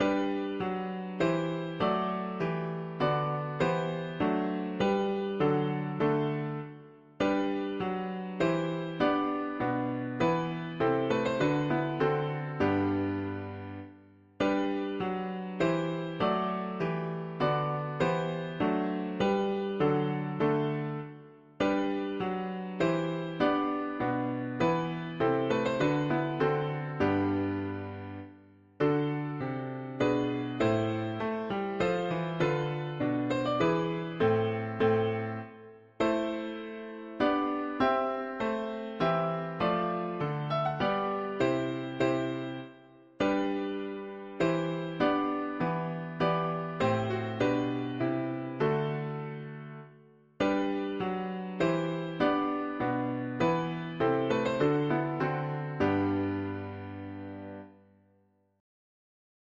Tags english christian 4part